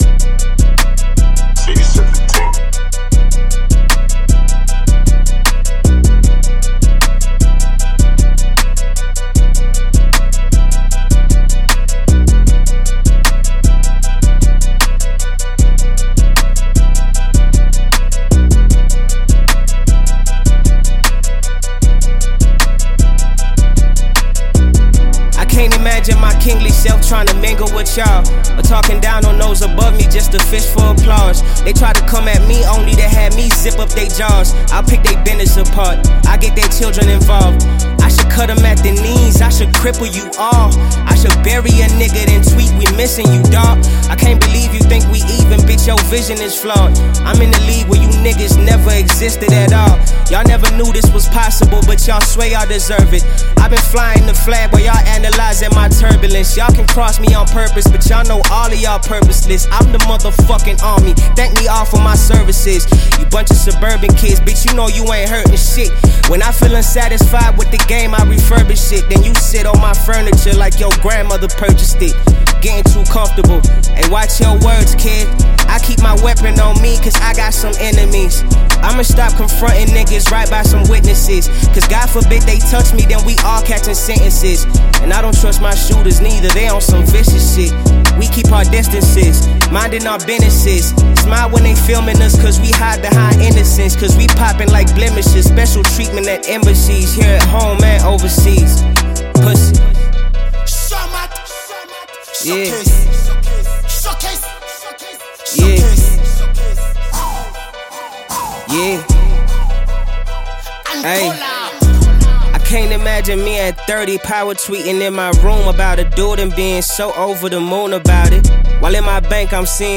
Popular South African rapper